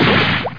00850_Sound_Bash.mp3